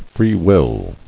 [free·will]